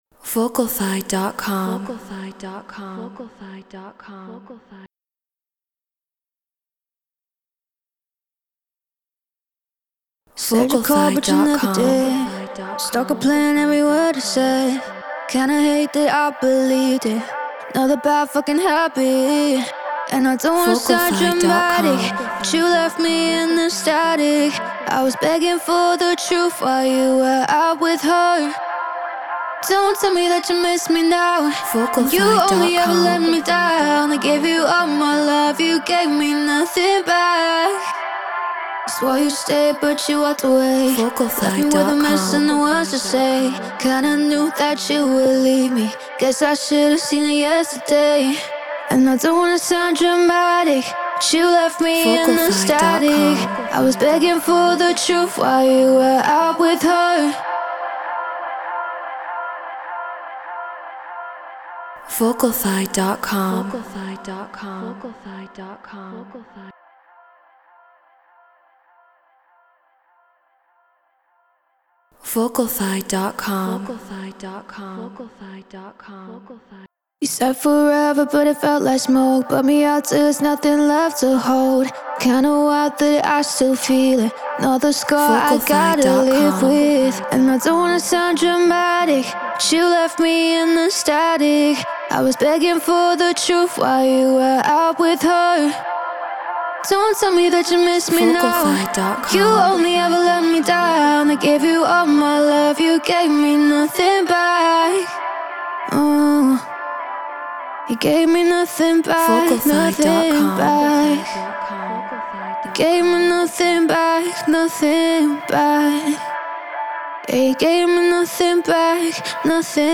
Future Pop 100 BPM Fmaj